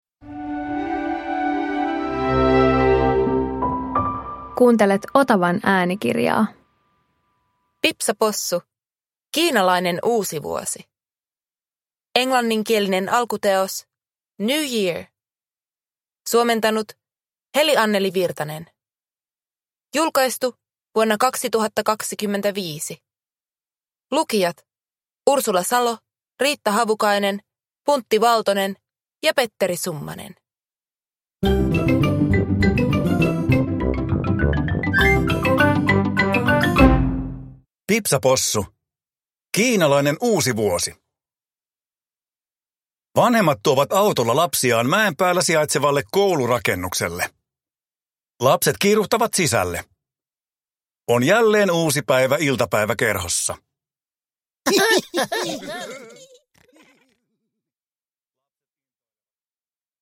Pipsa Possu - Kiinalainen uusivuosi – Ljudbok